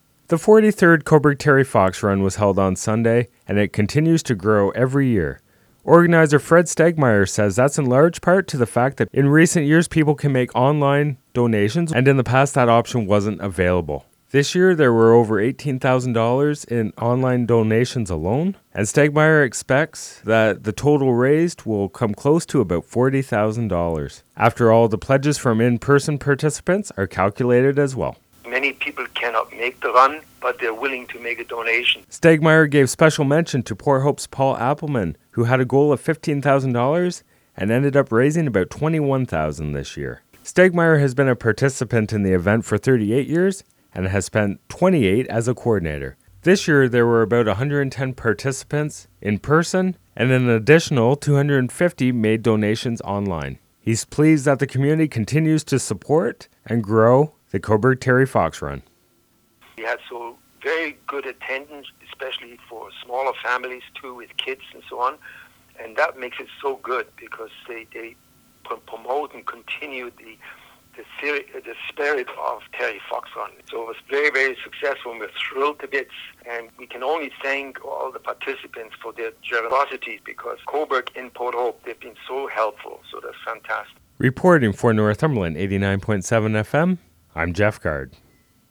TerryFoxRun-report.mp3